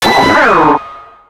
Cri de Lançargot dans Pokémon X et Y.